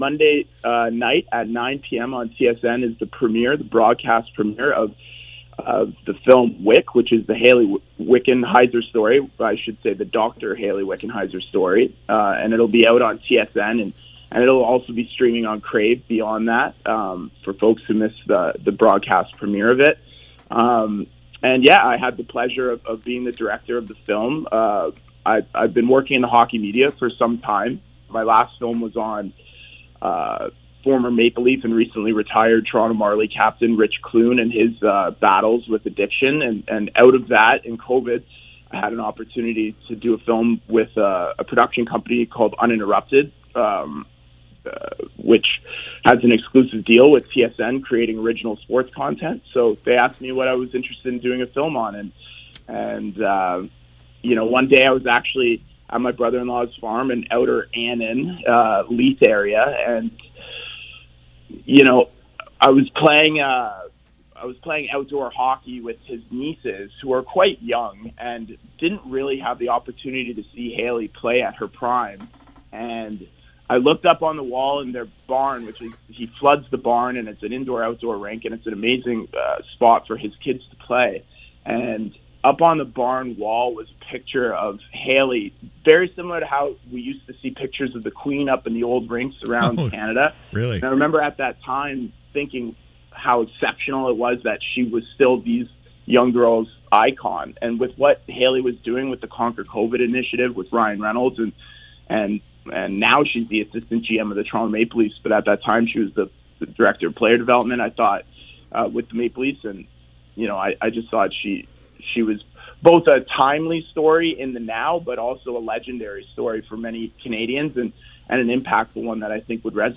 WICK. Interview